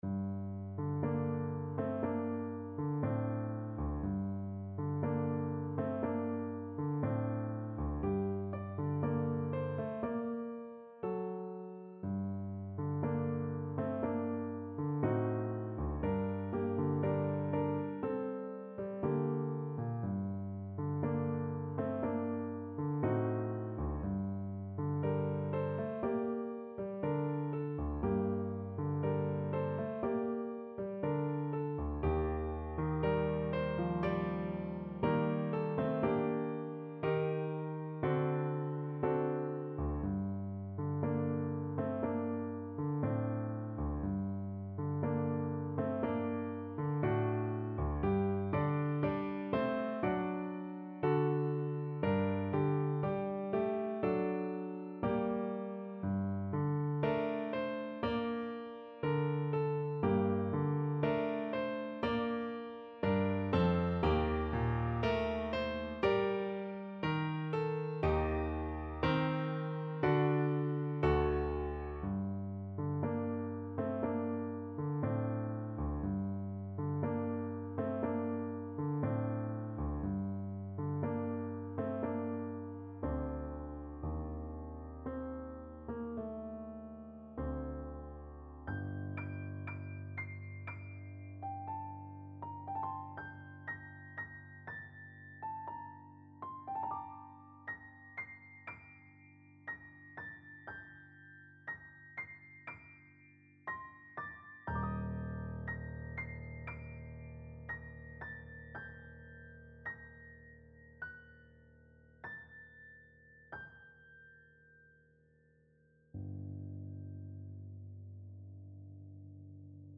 No parts available for this pieces as it is for solo piano.
G minor (Sounding Pitch) (View more G minor Music for Piano )
2/4 (View more 2/4 Music)
Slow, mysterious =c.60
Piano  (View more Intermediate Piano Music)
Traditional (View more Traditional Piano Music)